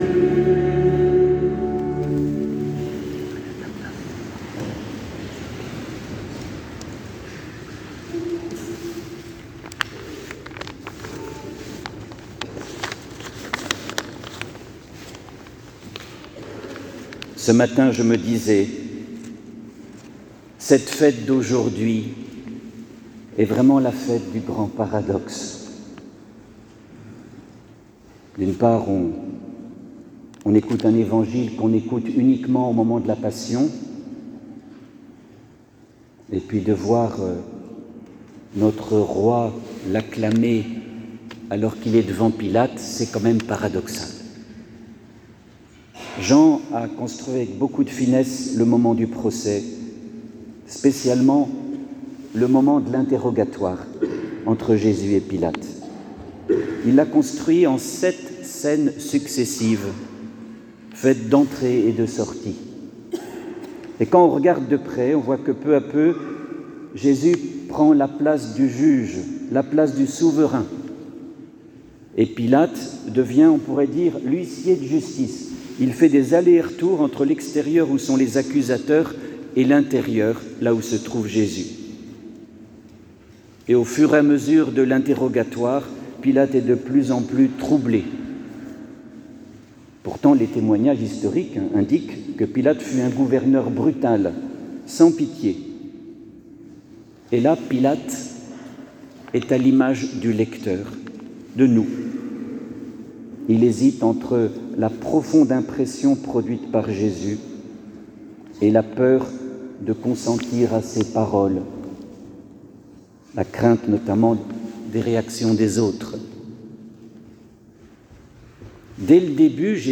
Homélie du 24 novembre : La fête du grand paradoxe Jean 18,33b-37
Vous trouverez ci-dessous l'enregistrement audio de l'homélie :